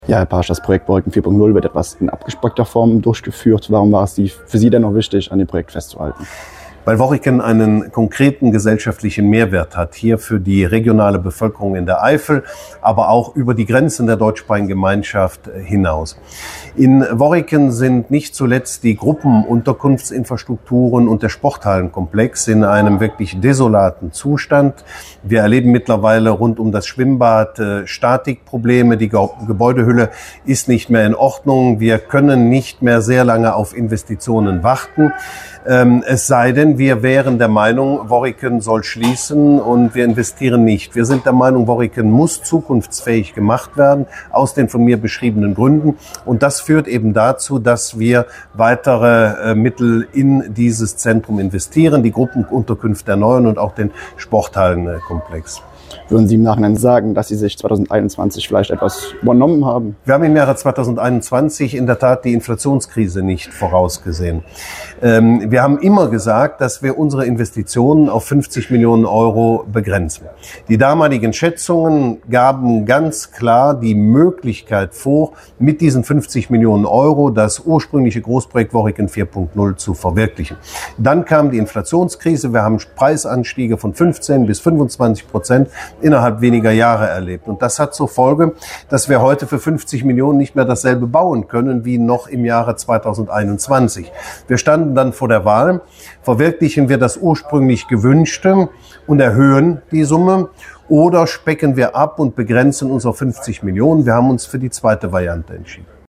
Das erklärte Ministerpräsident Oliver Paasch am Donnerstagnachmittag auf einer Pressekonferenz.